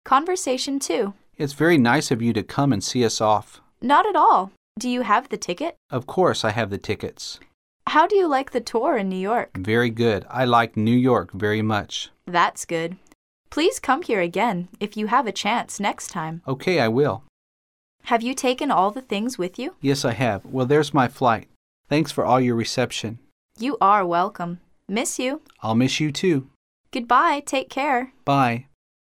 Conversation 2